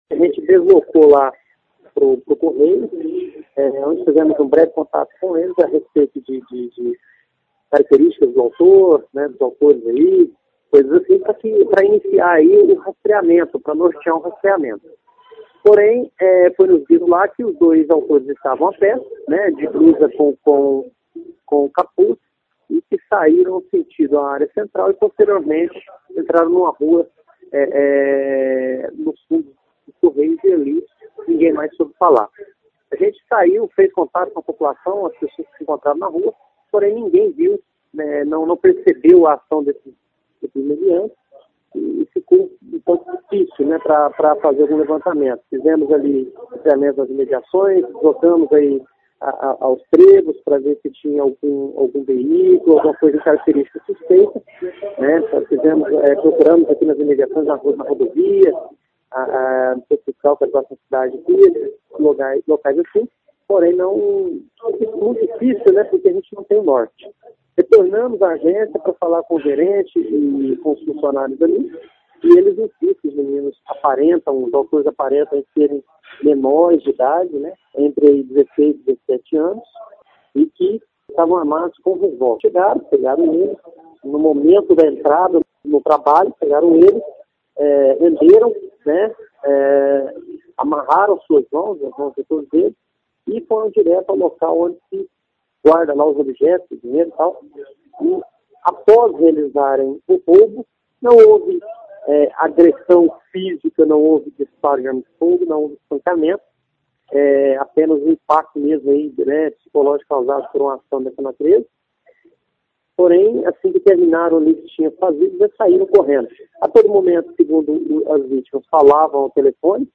A Agência dos Correios de Fronteira foi invadida na manhã de ontem (18/02), em que duas pessoas armadas renderam os funcionários que chegavam para o trabalho e levaram dinheiro e vários materiais. (Clique no player abaixo e ouça a entrevista).